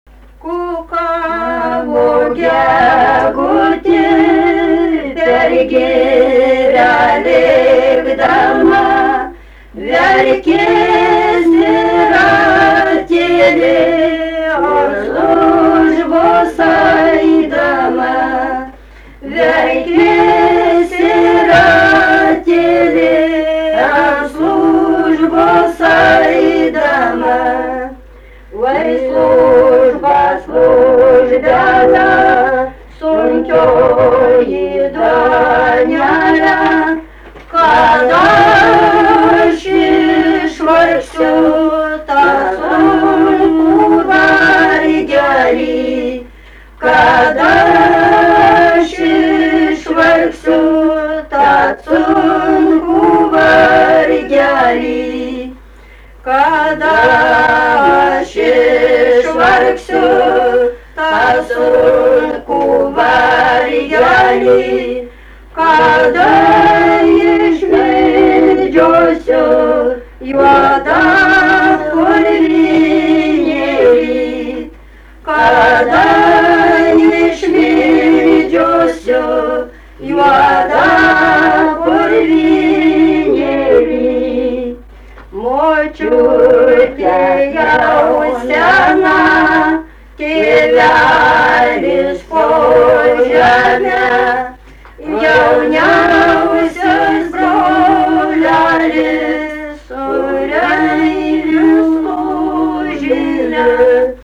daina
Rudnia
vokalinis